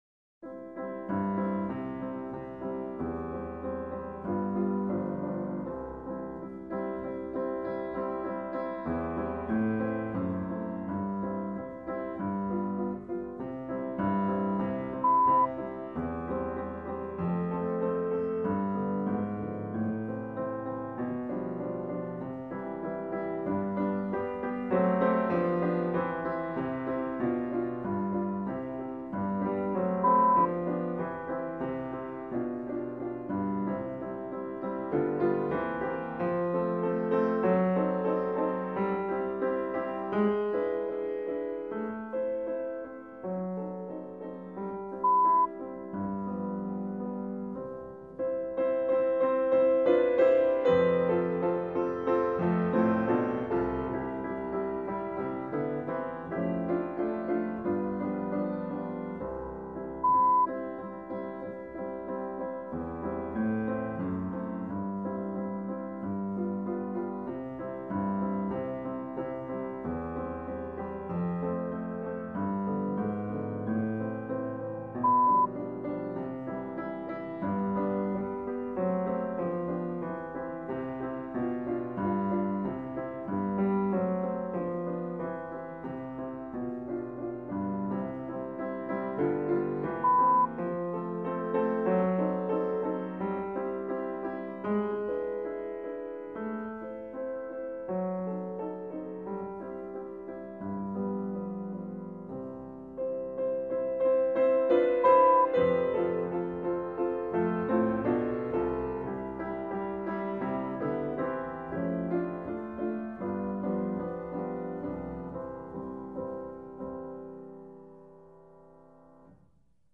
Recueil pour Mezzo-soprano/alto - Mezzo-Soprano